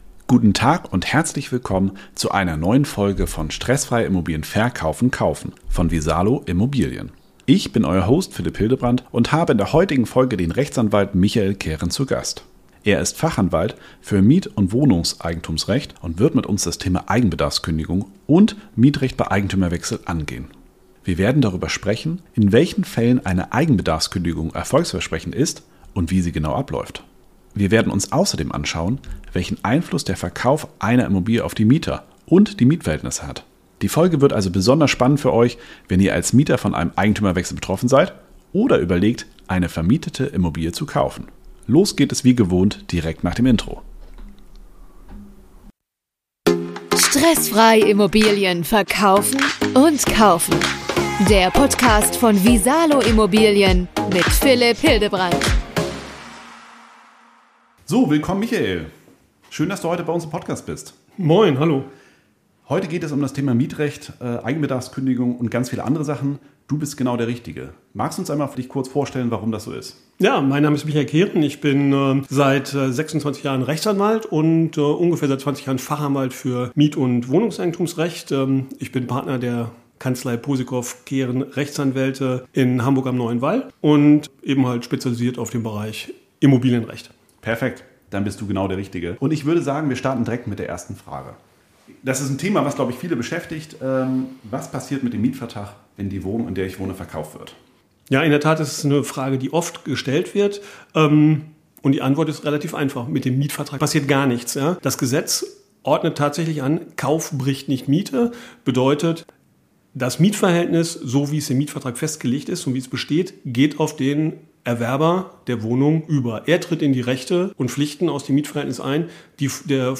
In dieser Folge haben wir den Rechtsanwalt